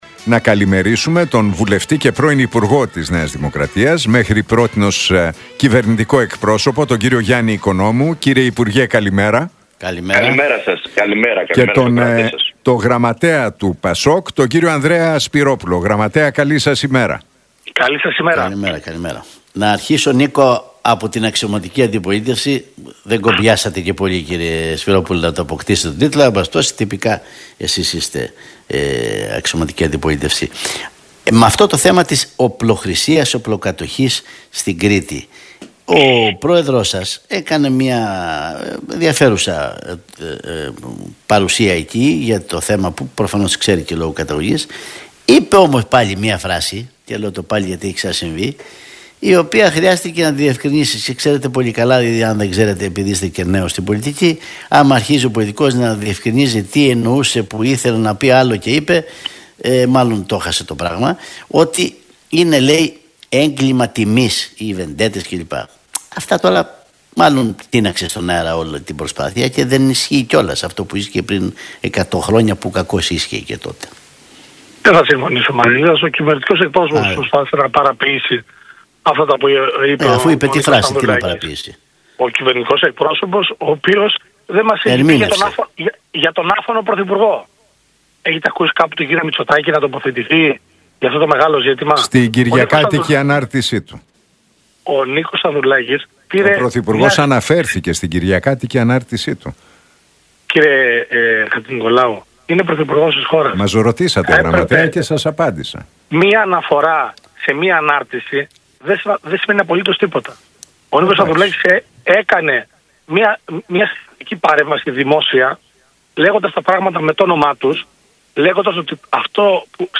Debate Οικονόμου